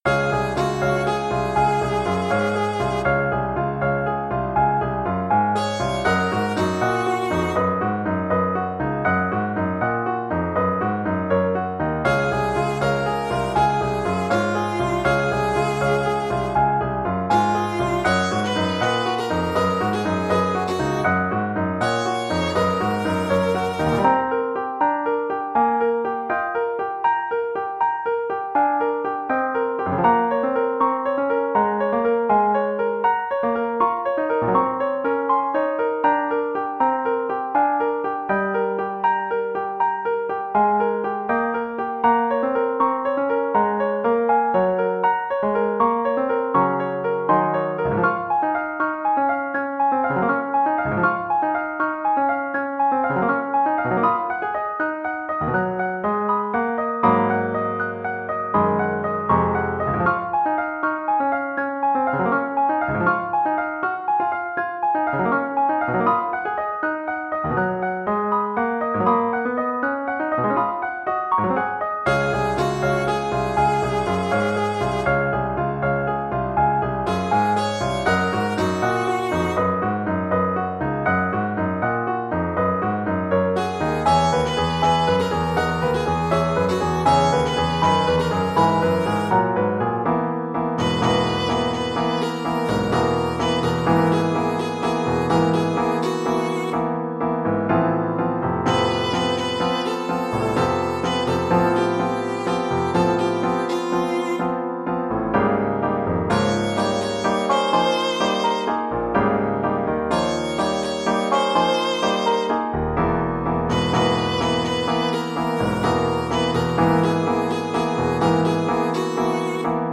The MP3s below are exported from the MIDI.